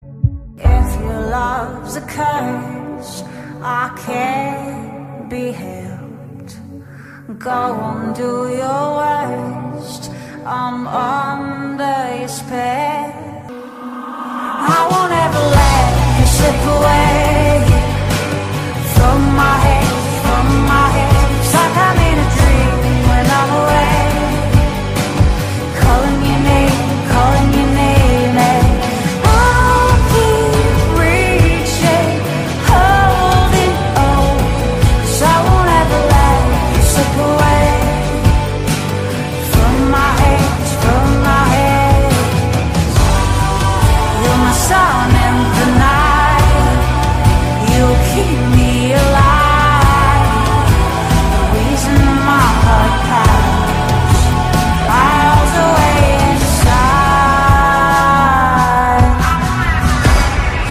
• Качество: 256, Stereo
спокойные
медленные
Pop Rock
легкий рок
ballads